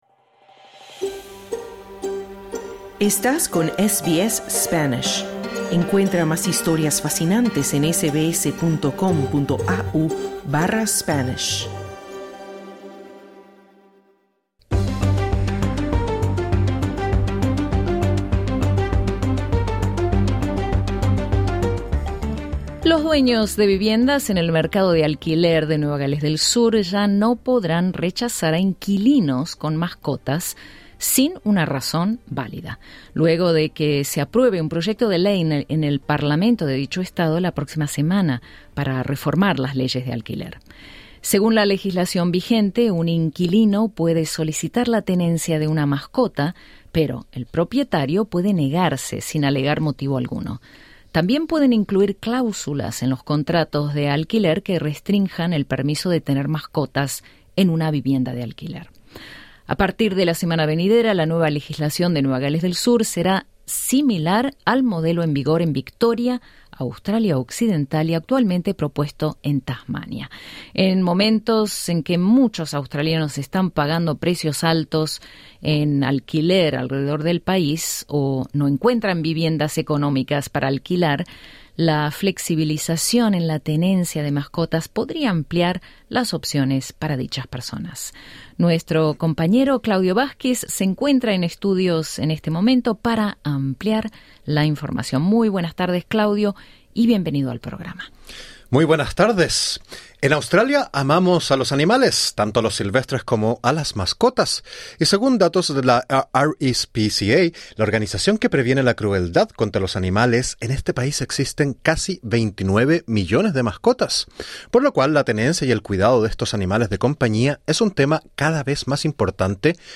Escucha el informe con la participación de la veterinaria